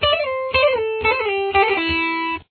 Here are some examples of some licks that you could create with it: